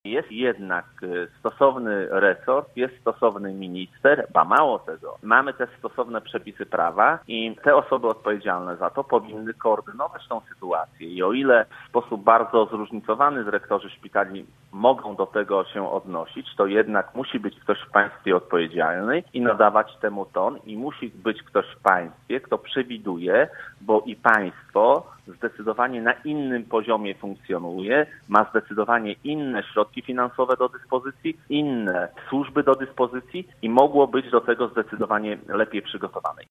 Szef lubuskich struktur Platformy Obywatelskiej, w Rozmowie Punkt 9, ocenił działania profilaktyczne podjęte w związku z rozprzestrzenianiem się patogenu.